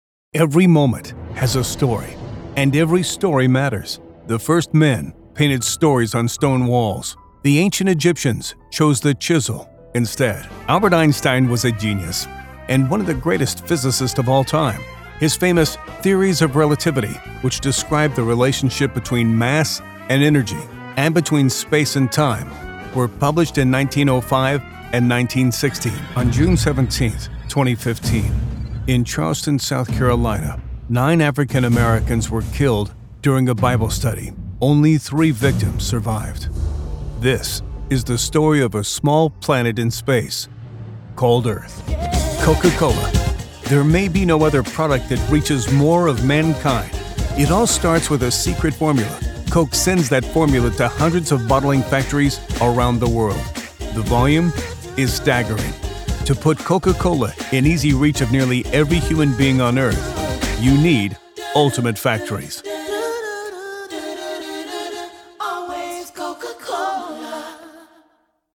Male
Trans-Atlantic
I have a dynamic voice range, from conversational and relatable, to energetic and powerful announcer.
Microphone: Neumann U87, Sennheiser MKH416